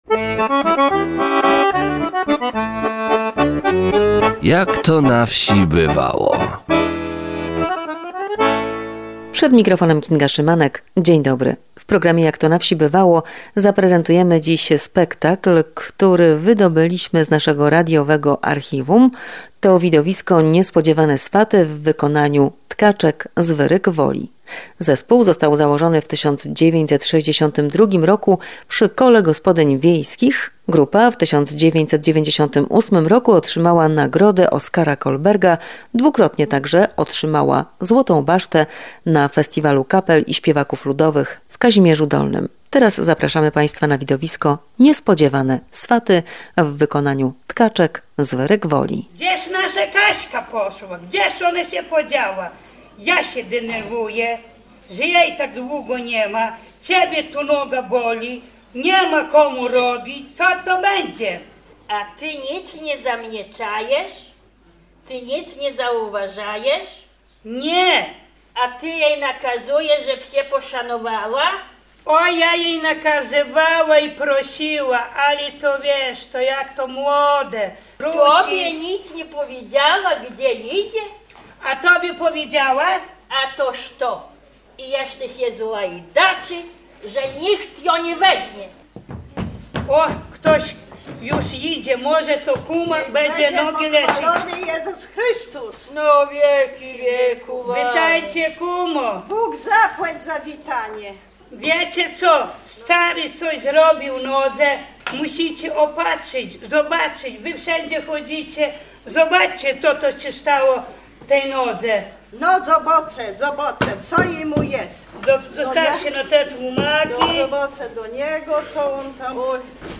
Widowisko obrzędowe "Niespodziewane swaty" w wykonaniu Tkaczek z Wyryk Woli.